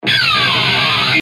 ロックな効果音をご自由にダウンロードして下さい。
Distortion Sound Guitar
Distortionキュウゥン(ピッキングスクラッチ)01 23.96 KB